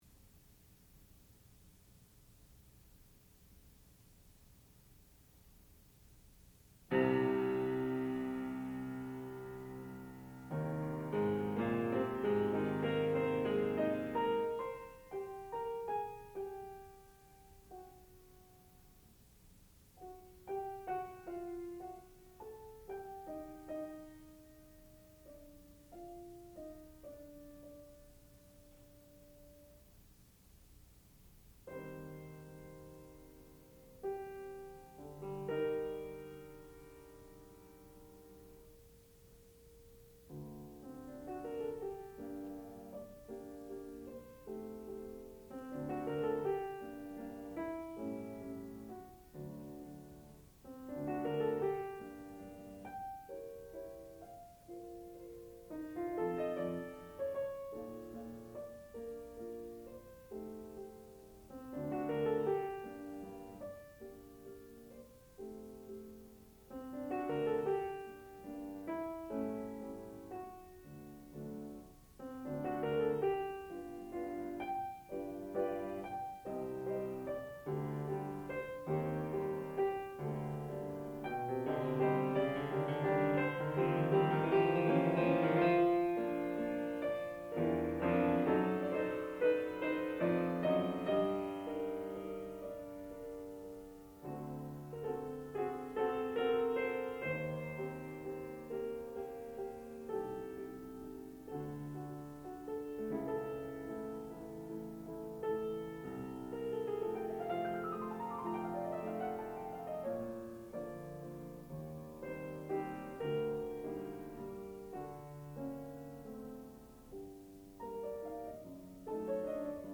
sound recording-musical
classical music
Student Recital
piano